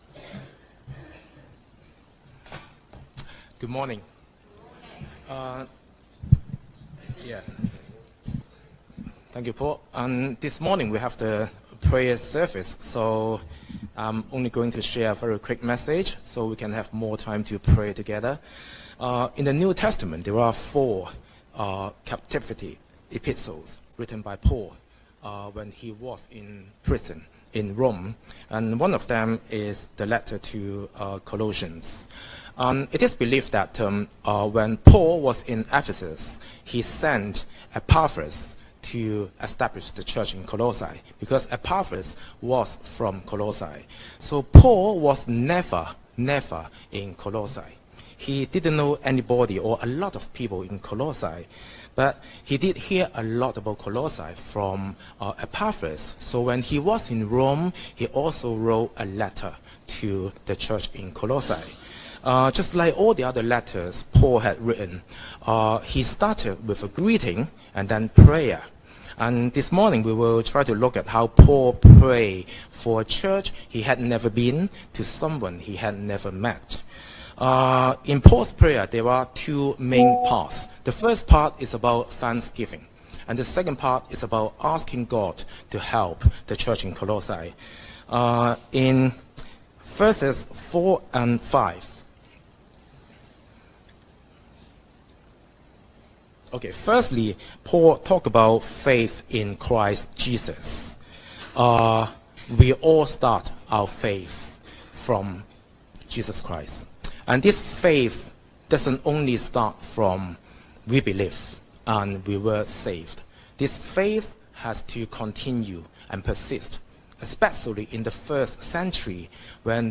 Prayer Service